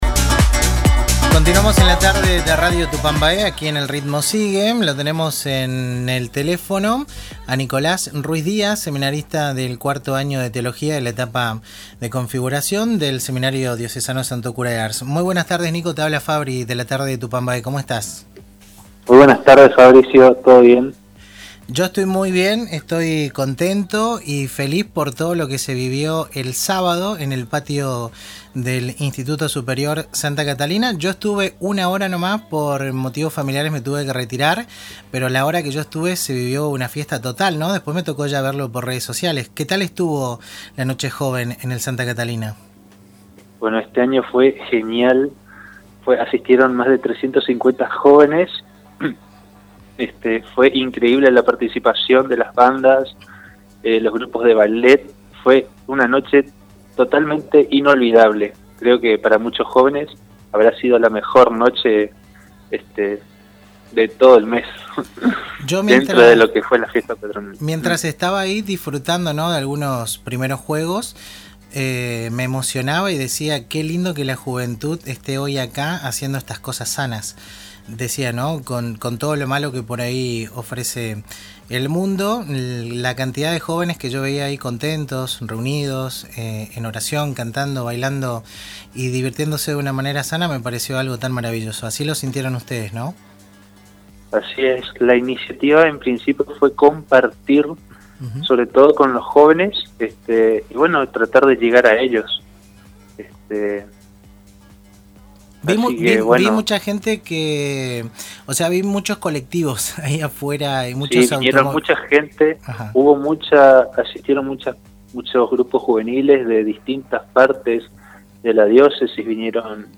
BannerPrincipal Entrevistas